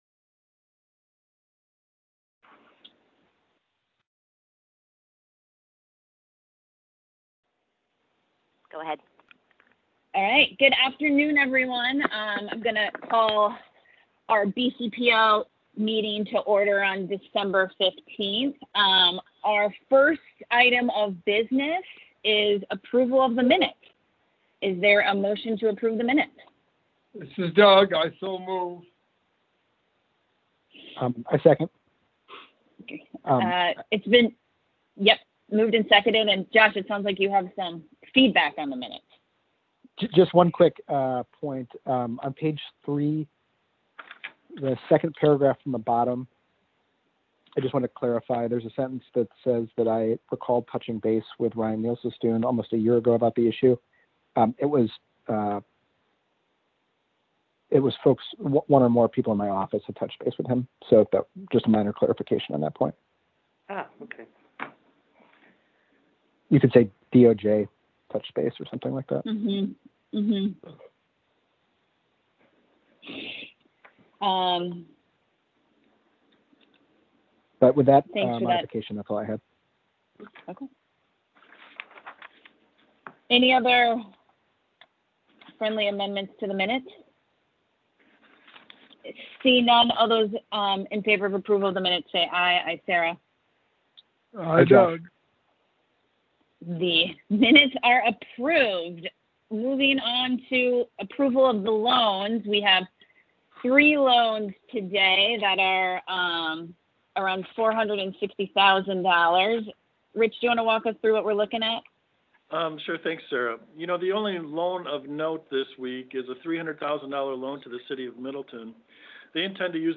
Regular Board Meeting